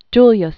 (jlyəs) Originally Giuliano della Rovere. 1443-1513.